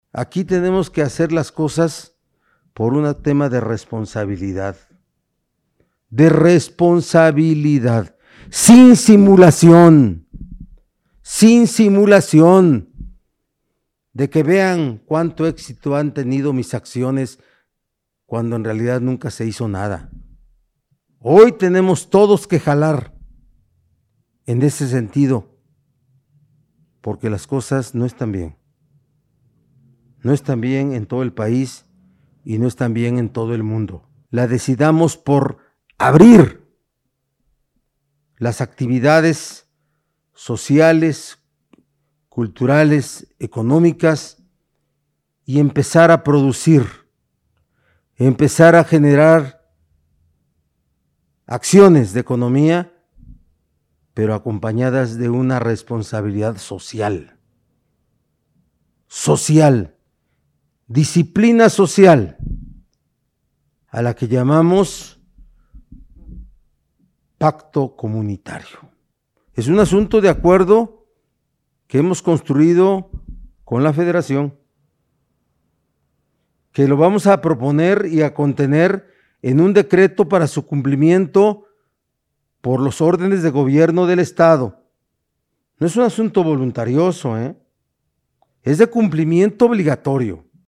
En su habitual conferencia de prensa, Barbosa Huerta destacó que los lineamientos de este plan de reactivación, que se construyó de manera conjunta con la federación, no son un asunto voluntario sino de cumplimiento, dado que se plasmarán en un decreto que se publicará en el Periódico Oficial del Estado.